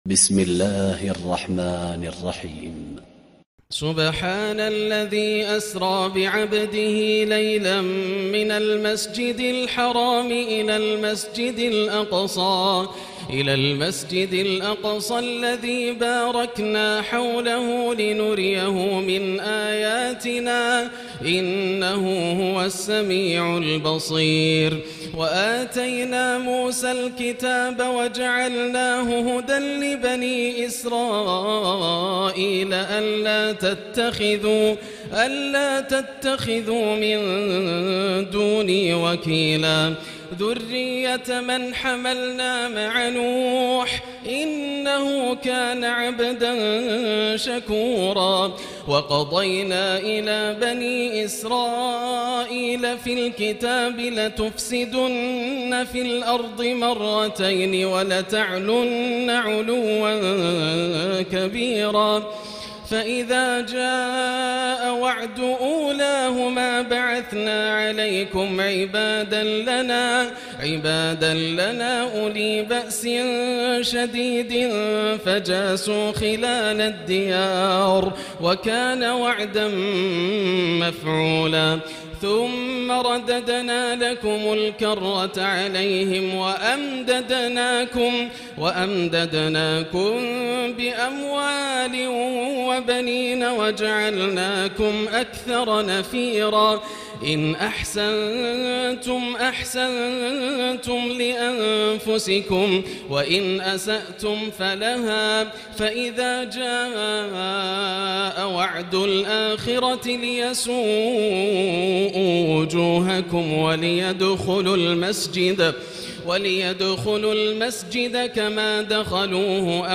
الليلة الرابعة عشر ترتيل رائع من سورة الإسراء1-100 > الليالي الكاملة > رمضان 1439هـ > التراويح - تلاوات ياسر الدوسري